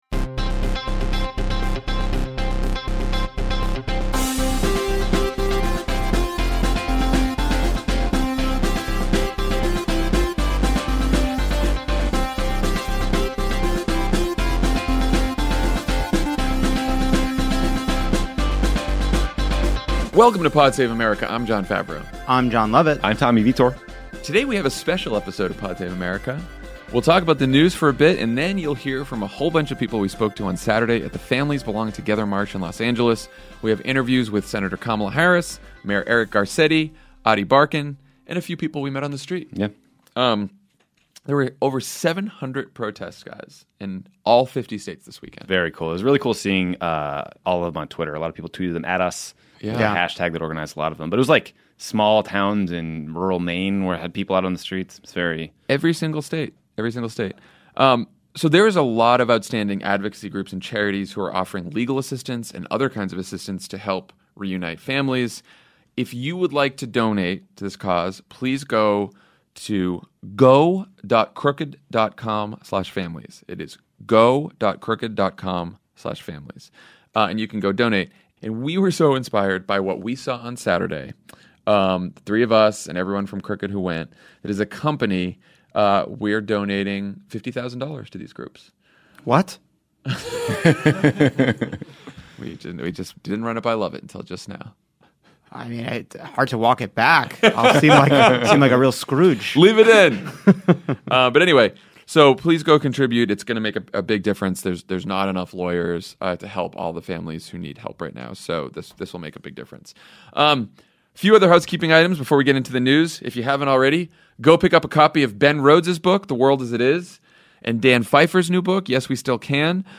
Republicans try to hide the fact that Trump will nominate a justice who will overturn Roe v. Wade, and more Democrats join calls to abolish ICE. Then we interview people at Saturday’s Families Belong Together march in Los Angeles, including Senator Kamala Harris, Mayor Eric Garcetti, DeRay Mckesson and activist Ady Barkan.